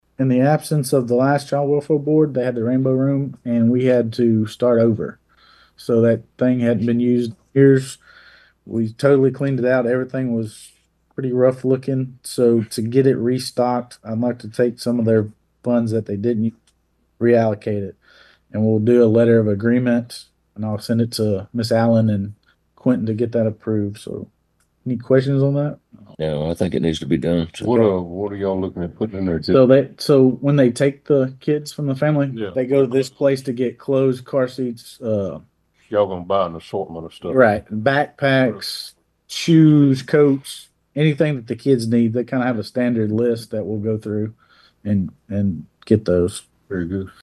At this week’s regular session of Walker County Commissioners Court, among several items considered, commissioners discussed and considered action on allocating $5,000 to the Child Welfare Board to restock the Walker County Rainbow Room from roll over funds.
Judge Colt Christian talked about the item with Commissioners White and Kuykendall.